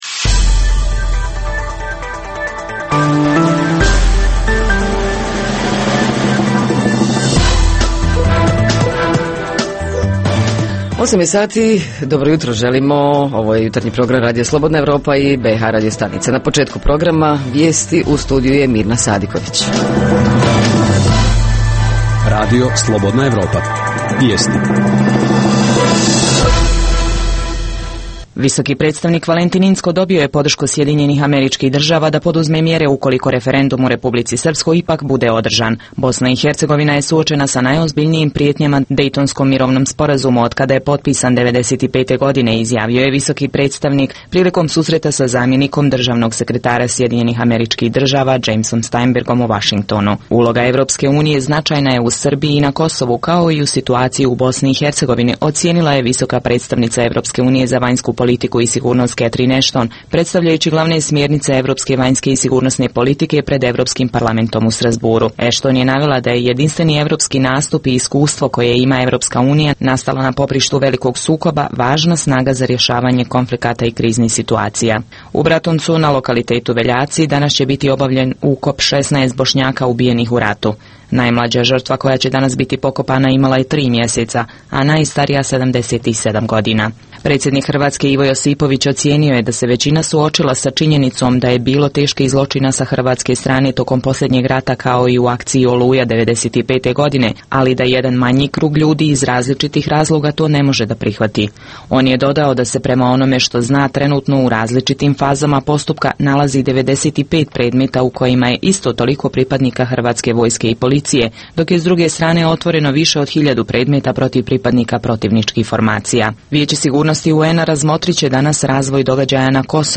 Jutarnji program za BiH koji se emituje uživo. Sadrži informacije, teme i analize o dešavanjima u BiH i regionu, a reporteri iz cijele BiH javljaju o najaktuelnijim događajima u njihovim sredinama.
Redovni sadržaji jutarnjeg programa za BiH su i vijesti i muzika.